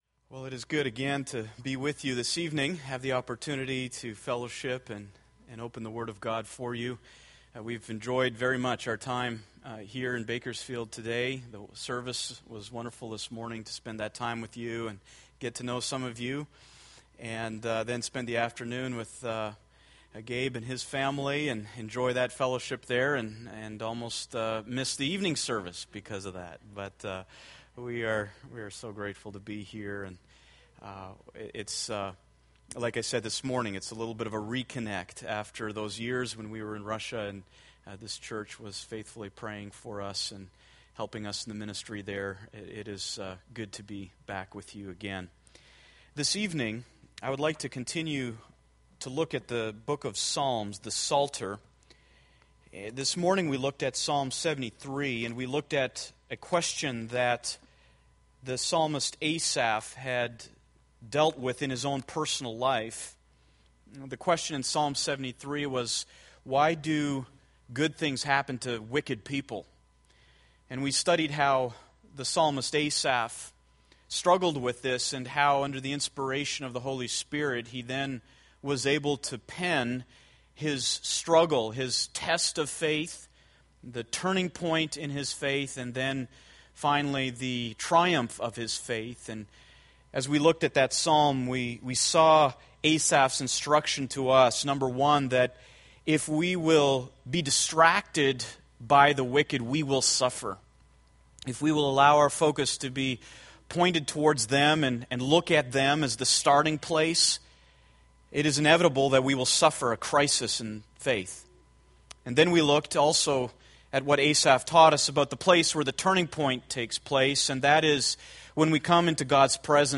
Psalms Sermon Series